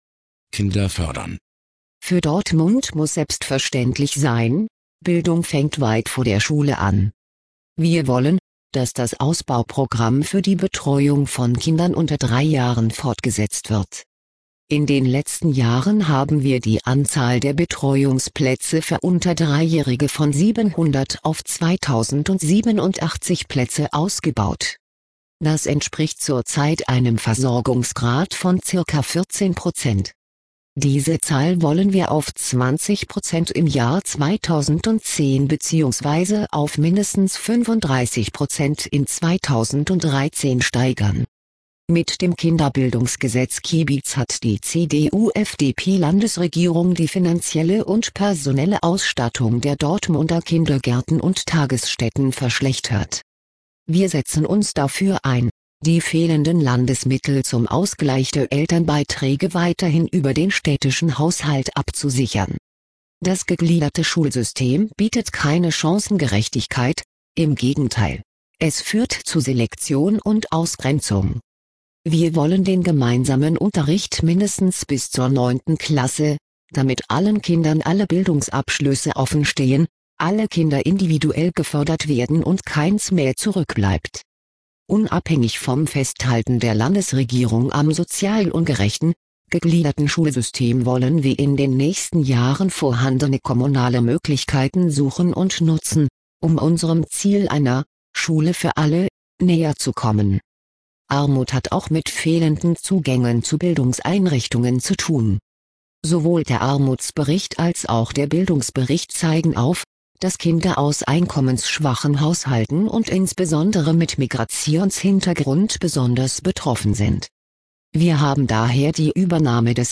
Auszüge aus unserem Kommunalwahlprogramm 2009 als Sprachversion